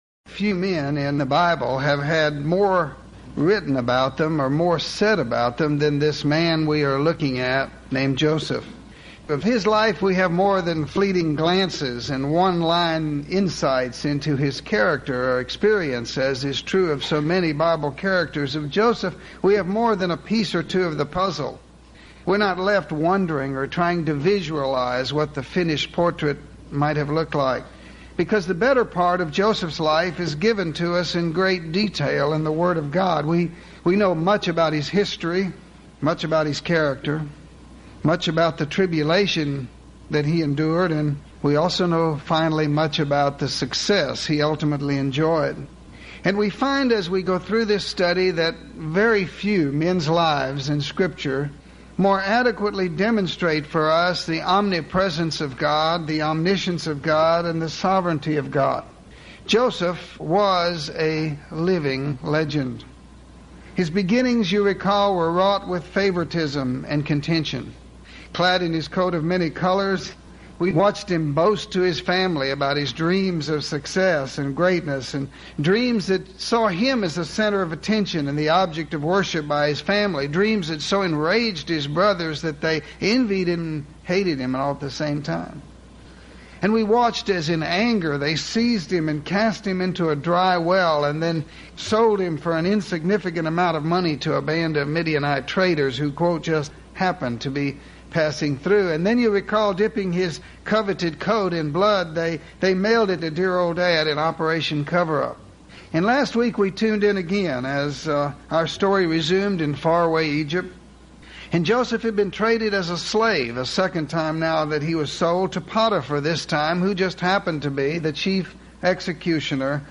These are questions that will be covered in this lesson on the life of Joseph.